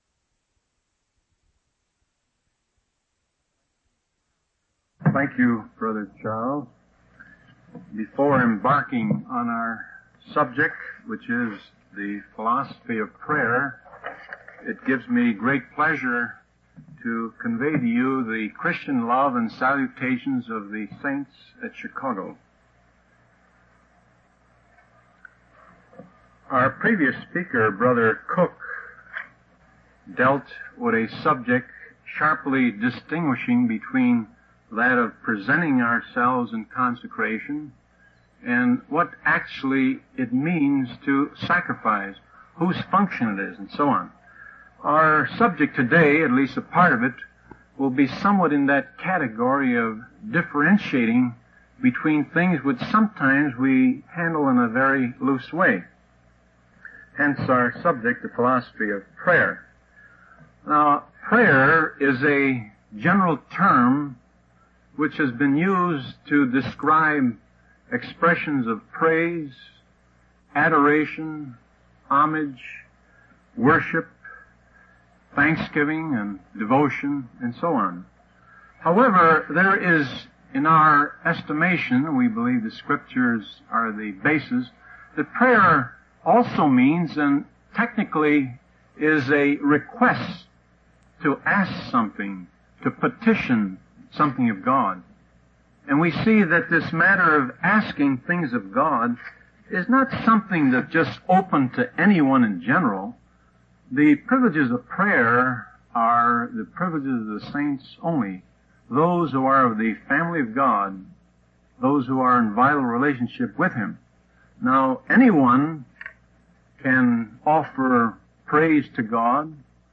From Type: "Discourse"
Silver Creek NB convention 1969